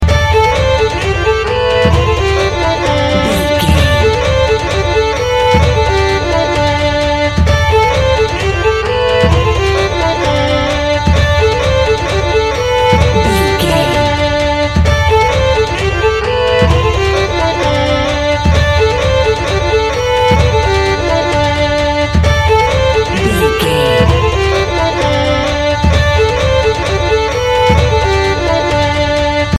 A great piece of celtic style music.
Aeolian/Minor
D
Fast
hammond organ
synth drums
synth leads
synth bass